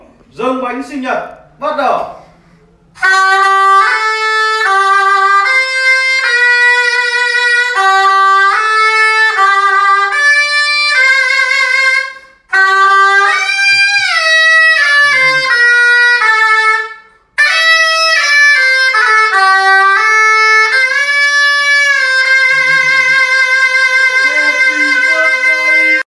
Thể loại: Nhạc chuông